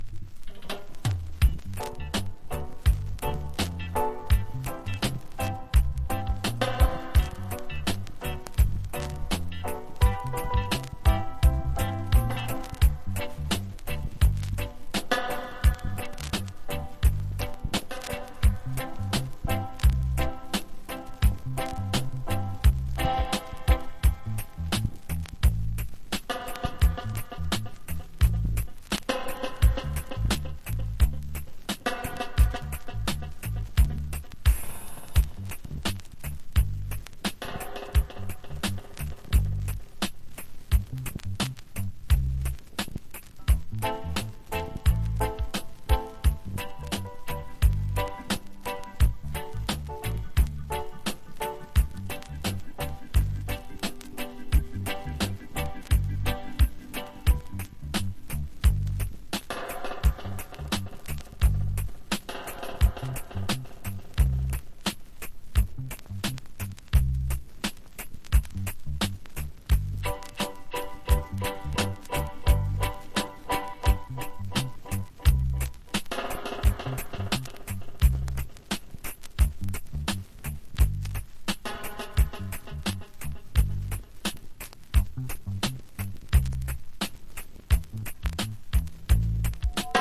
DUB / UK DUB / NEW ROOTS